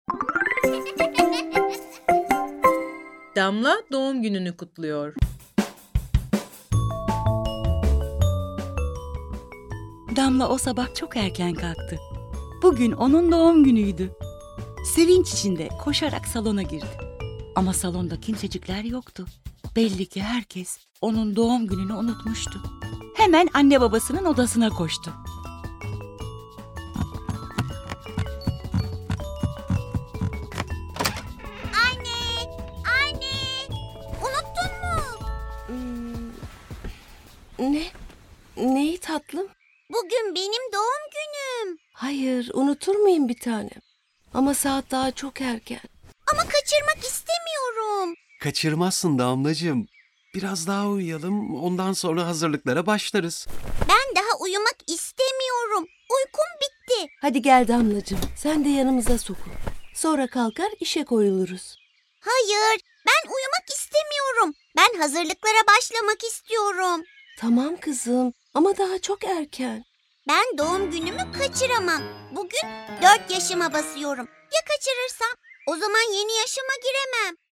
Damla Doğum Gününü Kutluyor Tiyatrosu